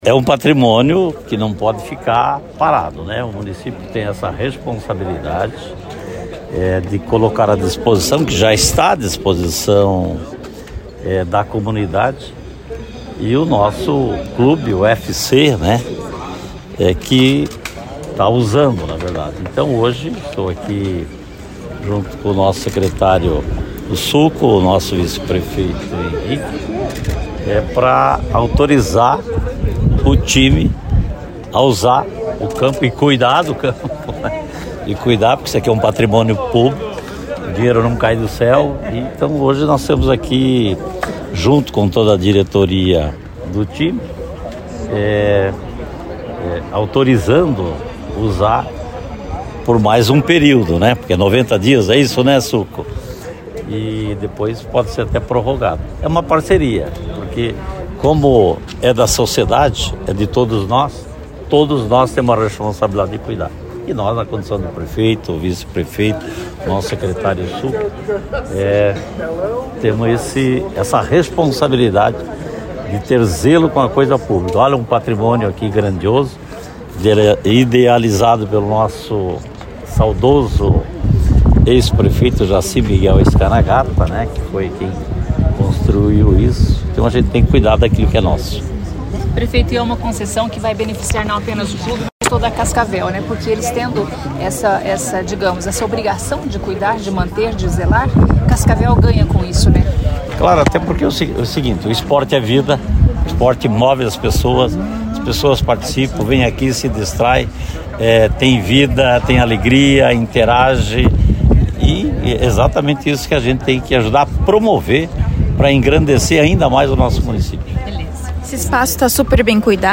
Player Ouça Renato Silva, prefeito de Cascavel